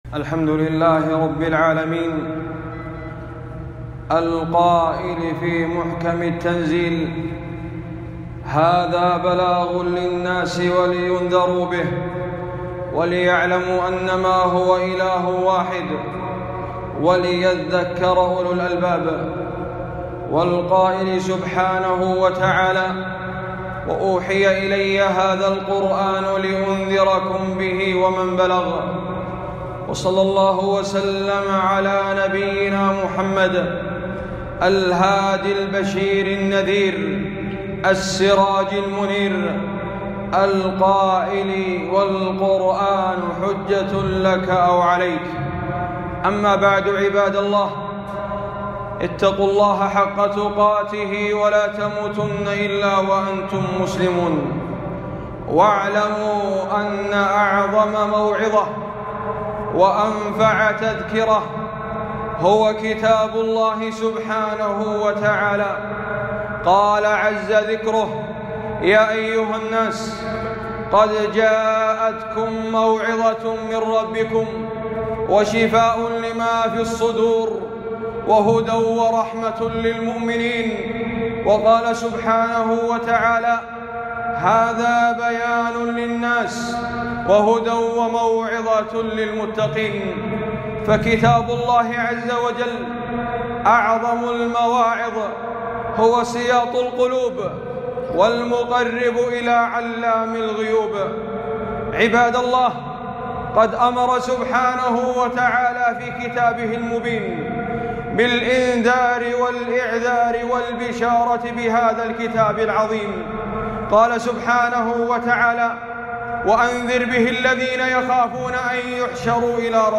خطبة - من مشاهد الساعة في القرآن